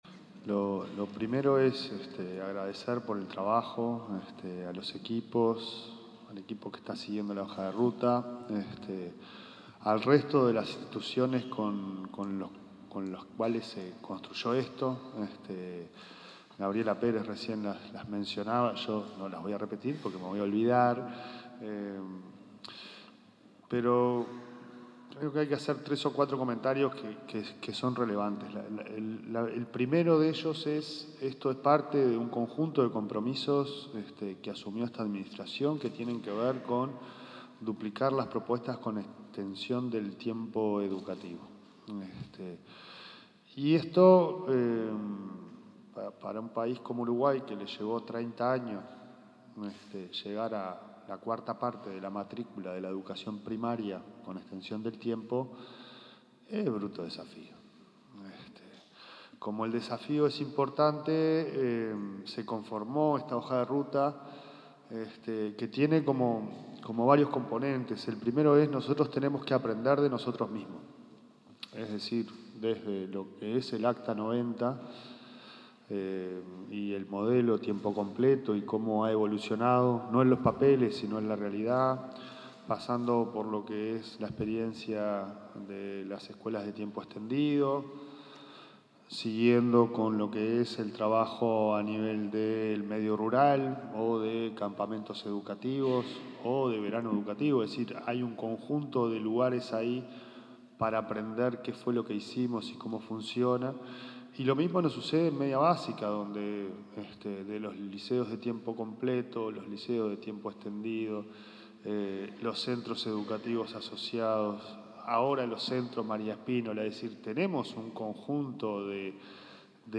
Palabras del presidente de la ANEP, Pablo Caggiani
En la presentación de la hoja de ruta de Extensión del Tiempo Pedagógico, se expresó el presidente de la Administración Nacional de Educación Pública,